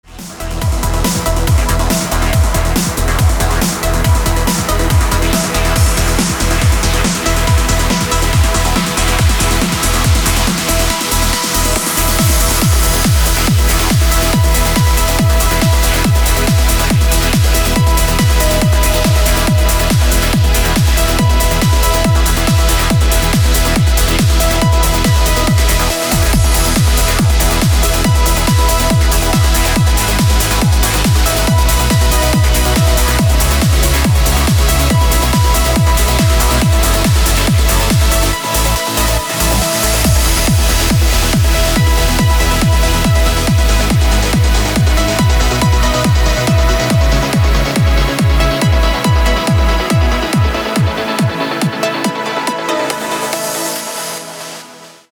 • Качество: 256, Stereo
громкие
dance
Electronic
электронная музыка
без слов
клавишные
club
Trance
Uplifting trance